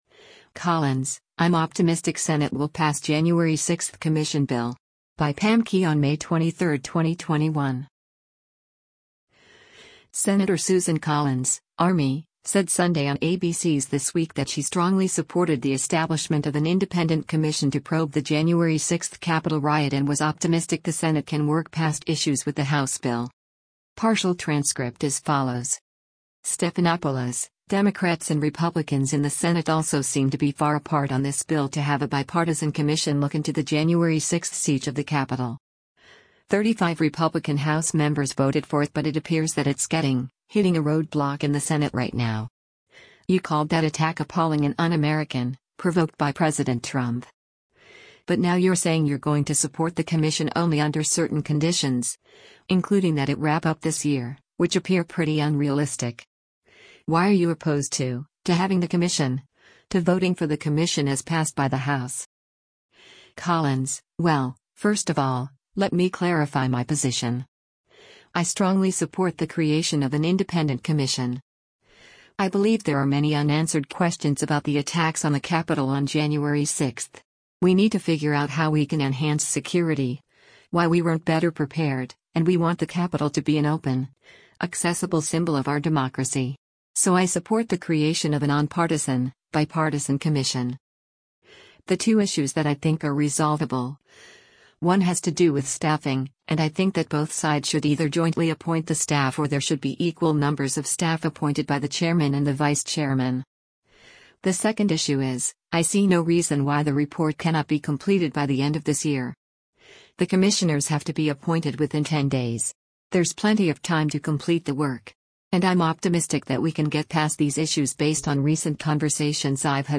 Senator Susan Collins (R-ME) said Sunday on ABC’s “This Week” that she “strongly” supported the establishment of an independent commission to probe the January 6 Capitol riot and was “optimistic” the Senate can work past issues with the House bill.